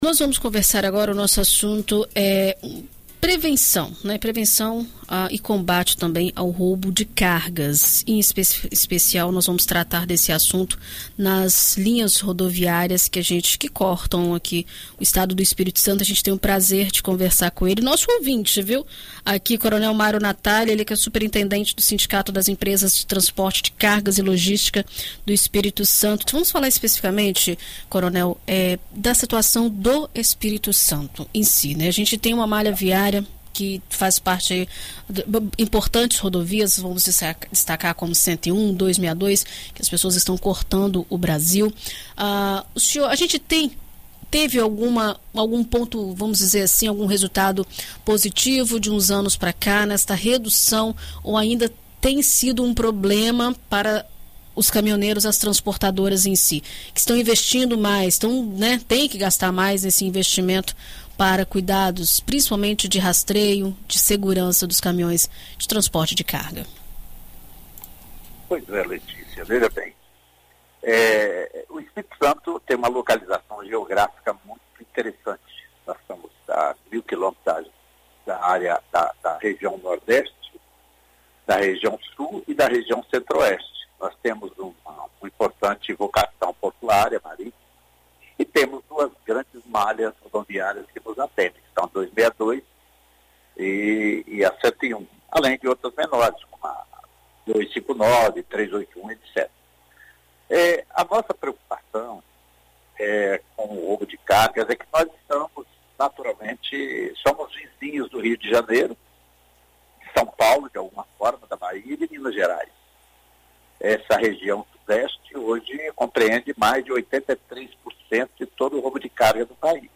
Em entrevista à BandNews FM Espírito Santo nesta terça-feira (28)